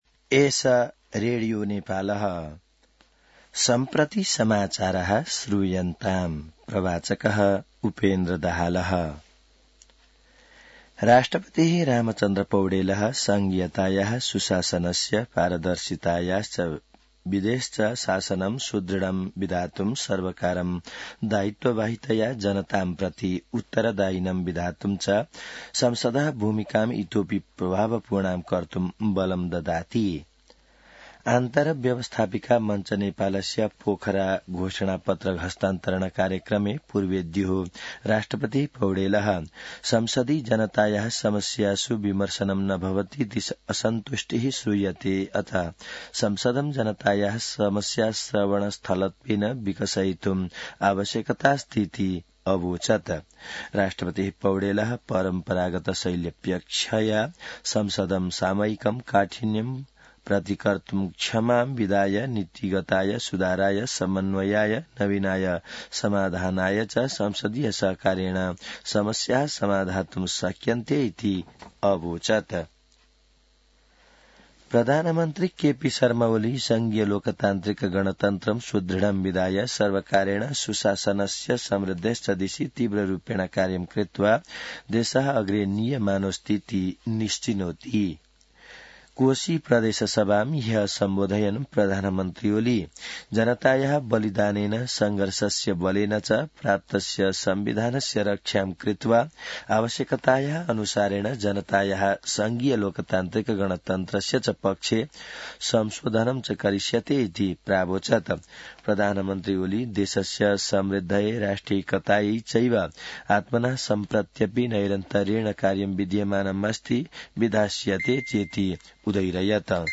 संस्कृत समाचार : २९ फागुन , २०८१